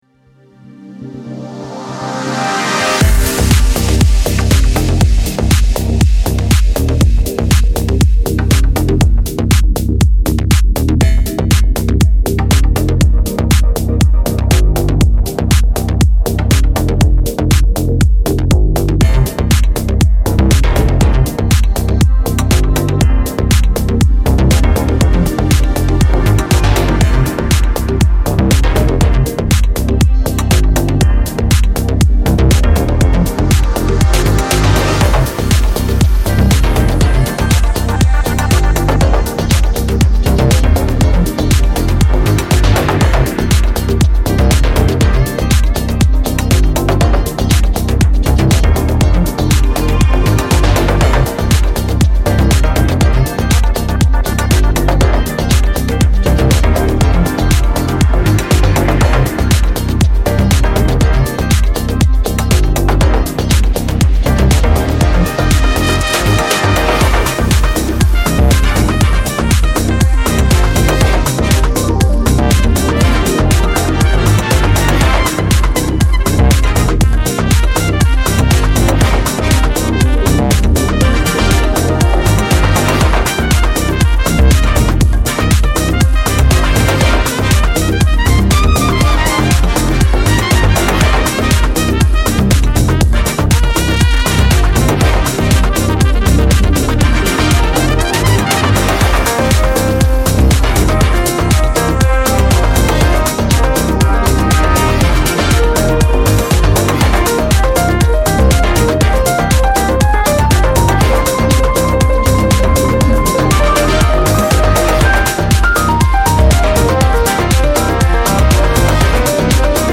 Как сведение?(House, Funk, Jazz)
Смесь нескольких стилей.